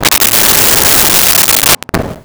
Wind Howl 02
Wind Howl 02.wav